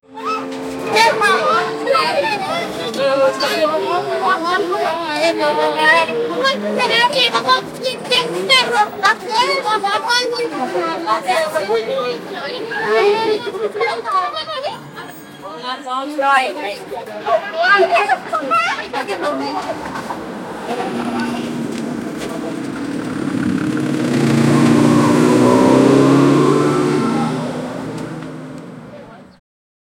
Group of excited teenagers talking, playing and moving around in a school bus.
group-of-excited-teenager-tveuafx5.wav